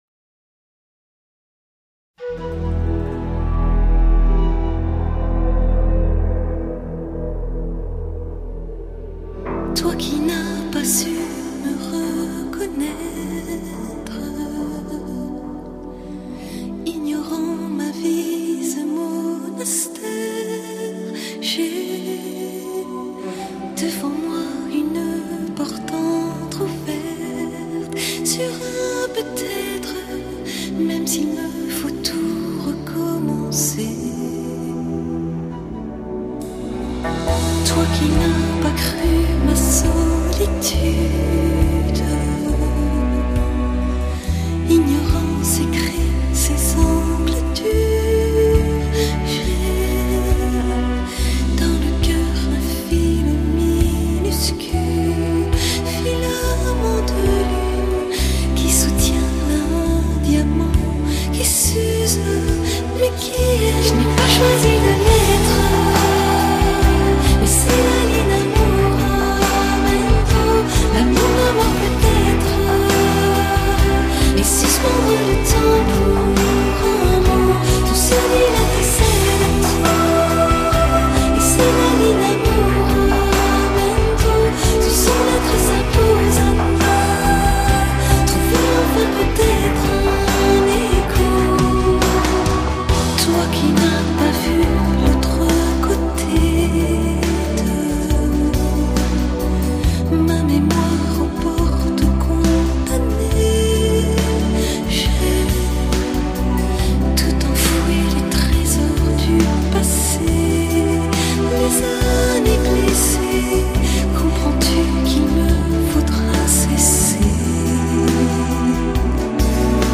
Genre: Pop, Pop-Rock, Electronica
她的歌声另类、却富亲和力，穿透力极强，宛如天籁。